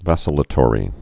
(văsə-lə-tôrē)